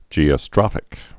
(jēə-strŏfĭk)